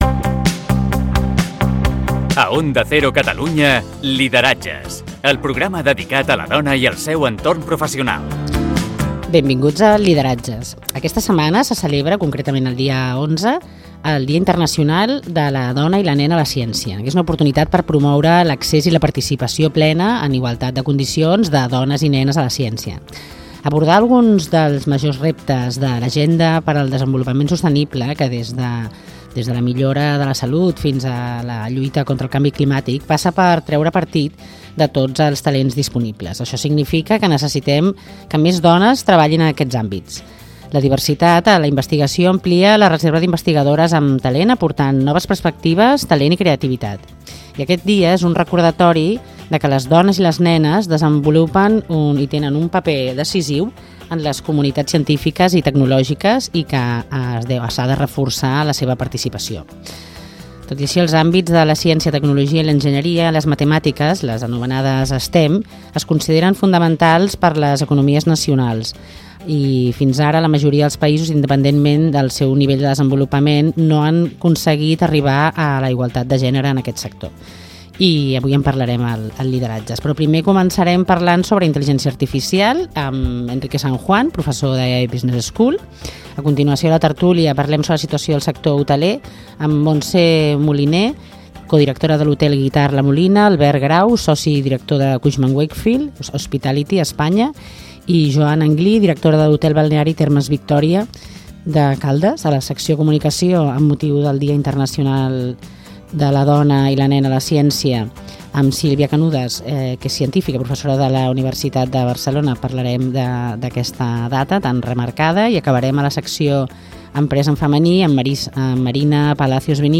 Sintonia i veu del programa, presentació amb la menció del Dia Internacional de la Dona i la Nena a la Ciència.
Divulgació
FM